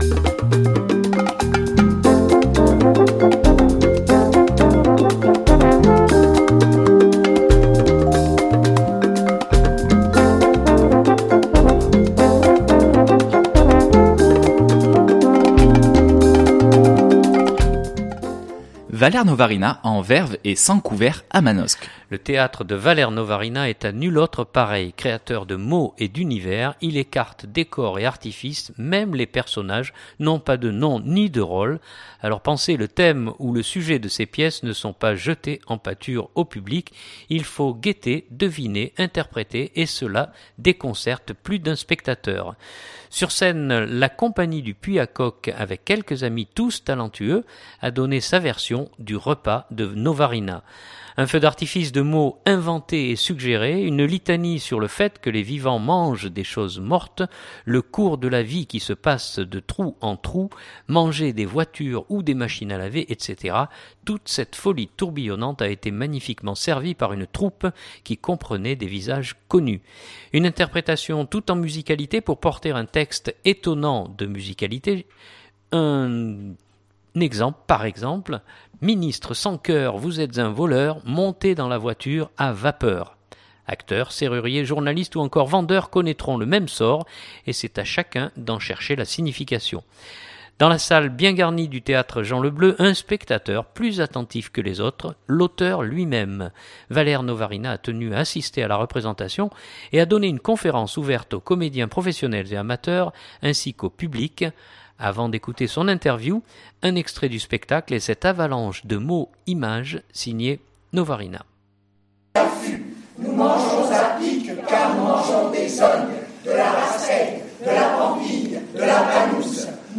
Une interprétation toute en musicalité pour porter un texte étonnant.
Valère Novarina a tenu à assister à la représentation et a donné une conférence ouverte aux comédiens professionnels et amateurs ainsi qu’au public. Avant d’écouter son interview un extrait du spectacle et cette avalanche de mots-images signée Novarina . écouter : Durée : 5'51'' Journal du 2016-12-16 Valère Novarina.mp3 (3.27 Mo)